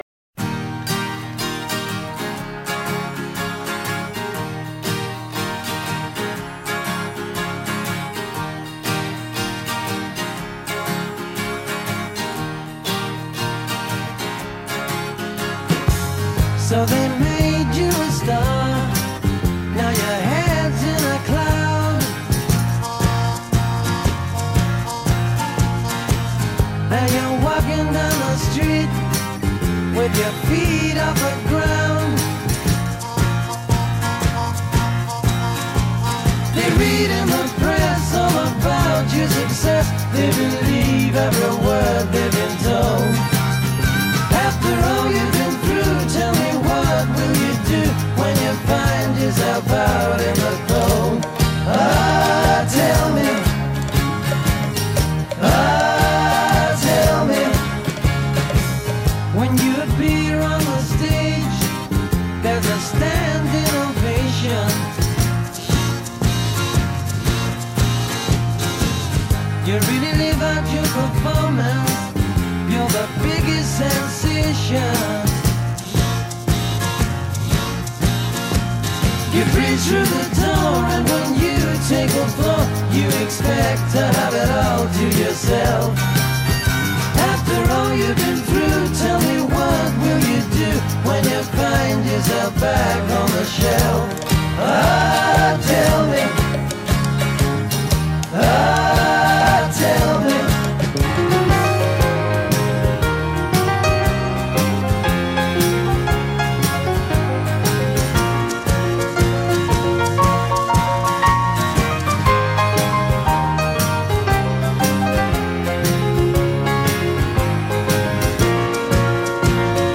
Los cuatro nuevos blues